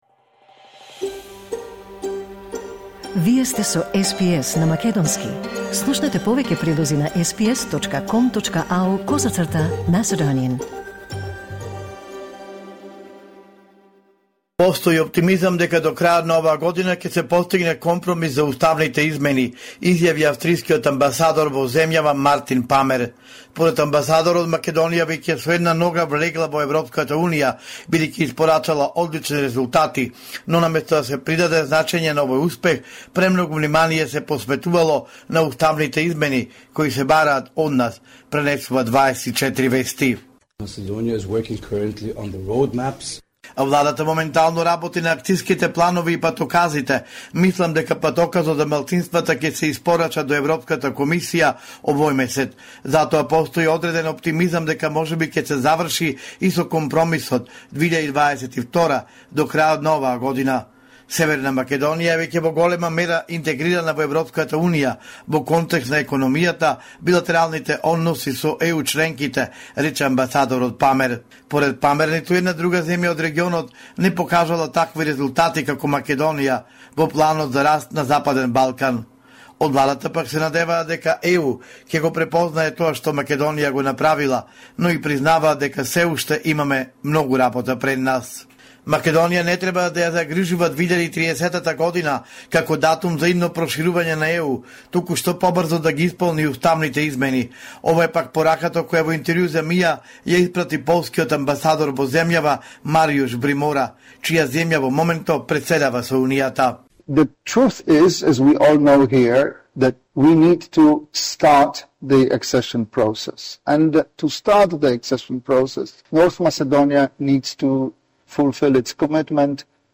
Извештај од Македонија 12 мај 2025